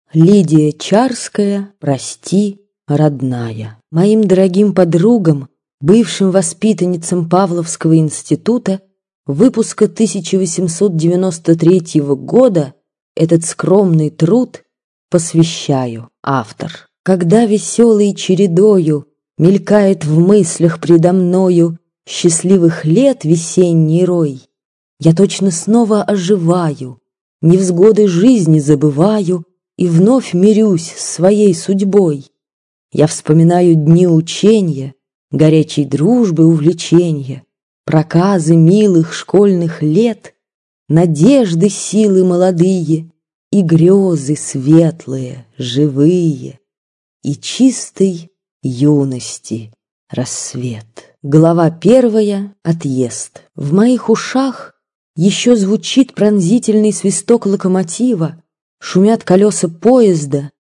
Аудиокнига Прости, родная | Библиотека аудиокниг